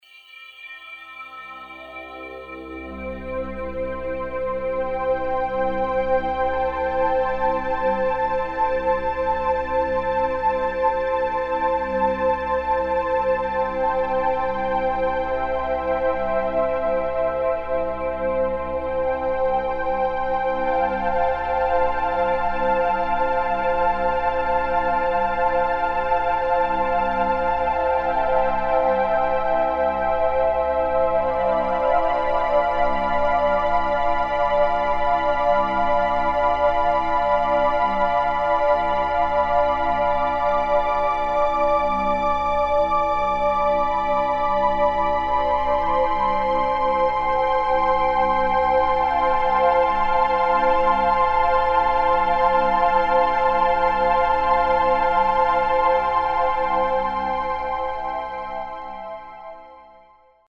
סולם הסולפיג'יו האבוד - תדרים עם מוזיקה מדיטטיבית